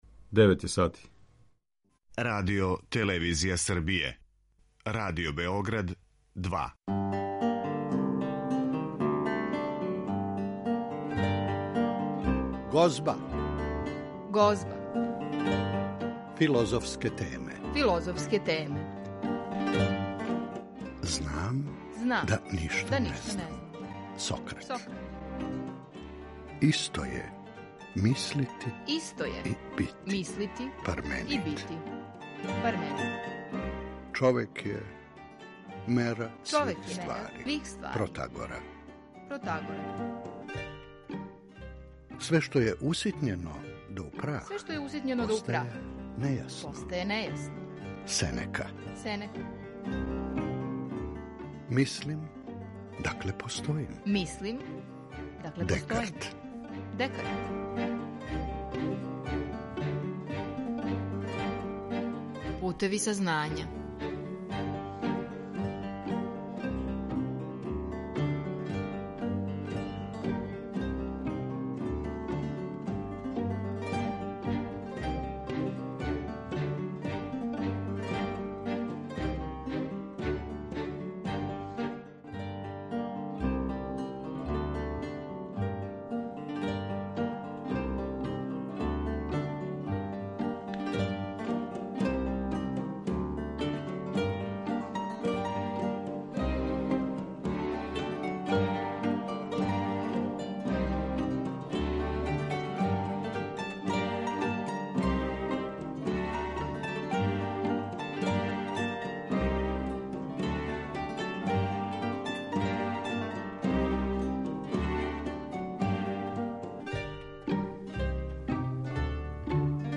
О посткапитализму разговарамо